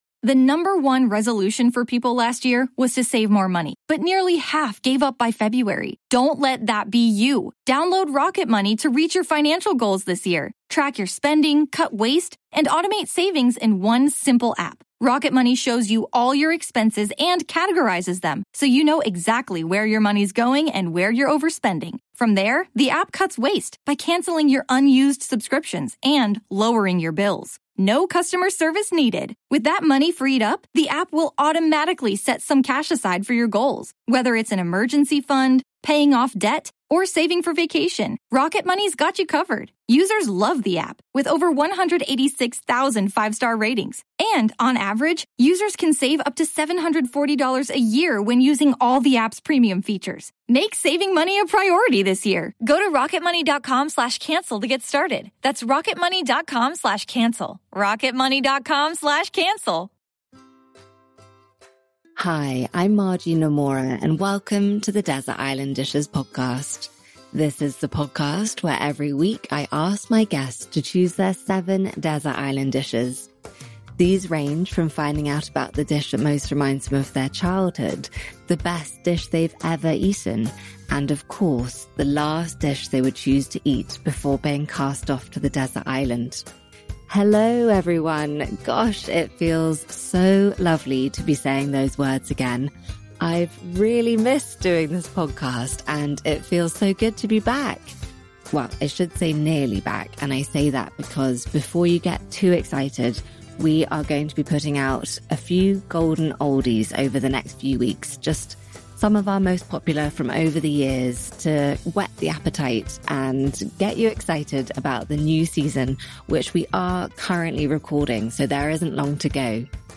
And I thought it would be appropriate to kick off with one of our most popular episodes to date… with the one and only Stanley Tucci, still pinching myself really that I got to go to his house and sit in his amazing artists studio at the bottom of his garden and talk all things food with him.